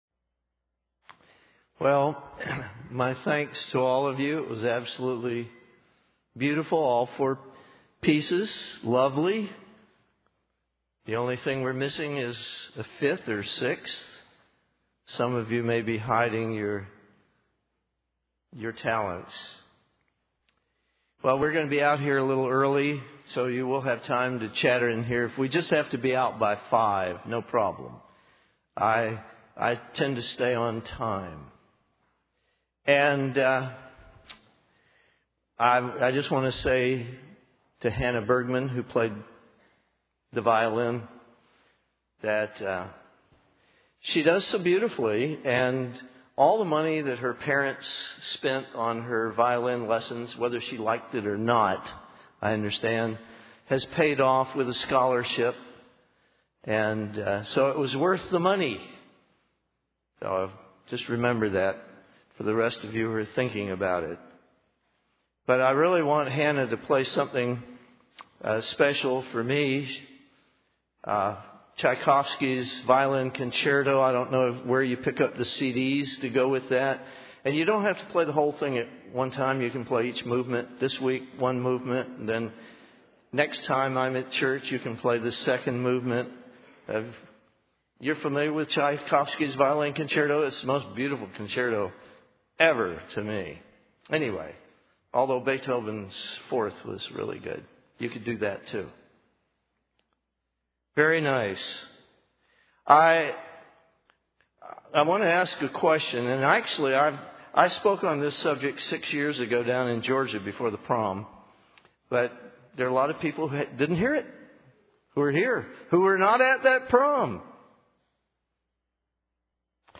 This message was given during the Southeast Regional Prom Weekend.
Given in Nashville, TN